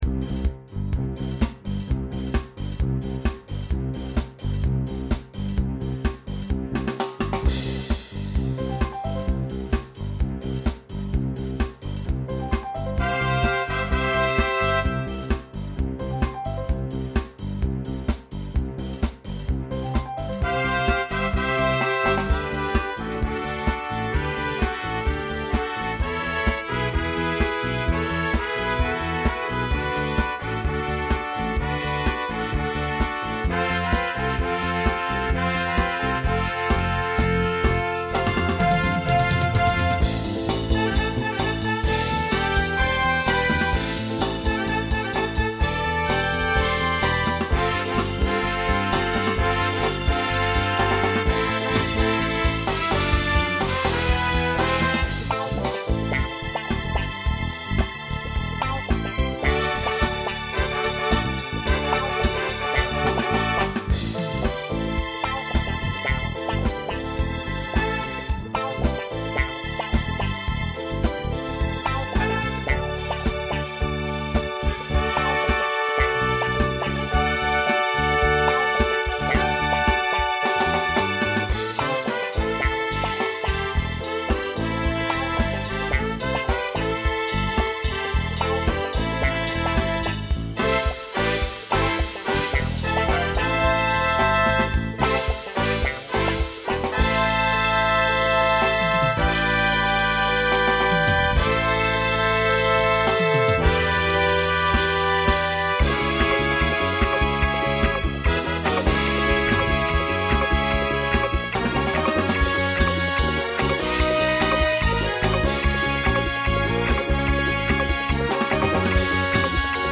Voicing Mixed Instrumental combo Genre Disco , Pop/Dance